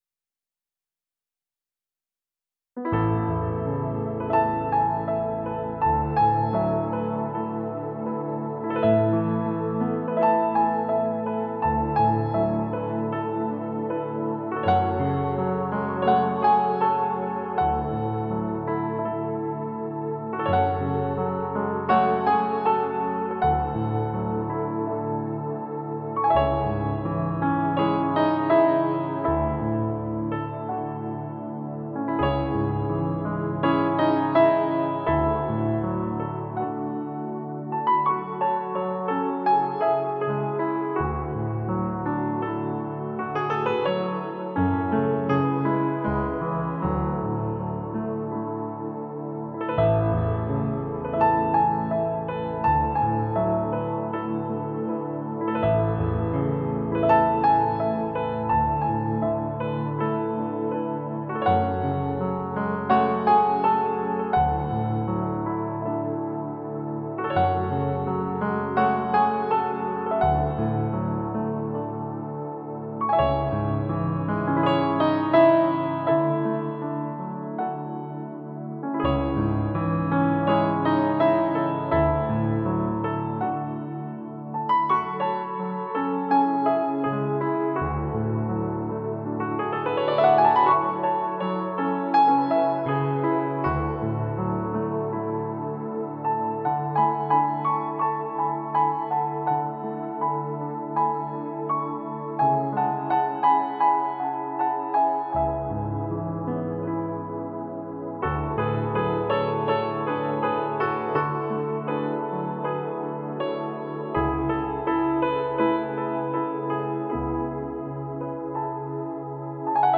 I’ve uploaded an instrumental that I’ve written entitled ” Rain”.